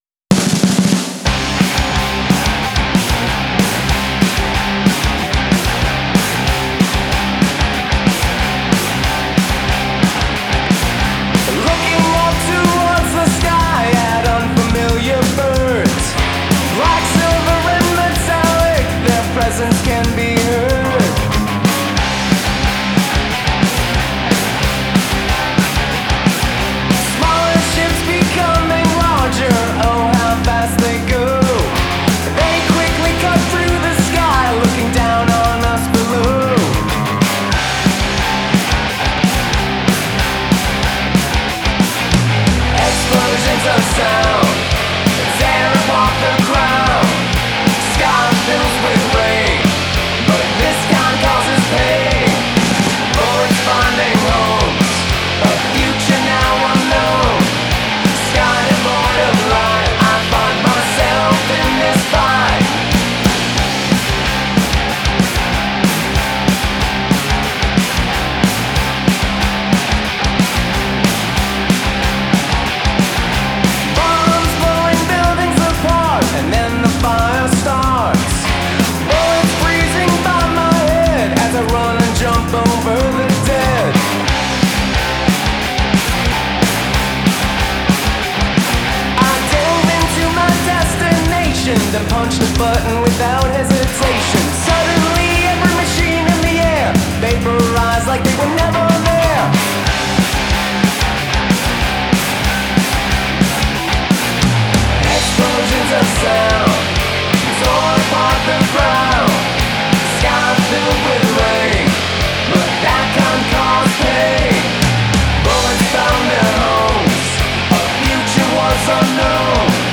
fast, quick
Largely a blistering punk song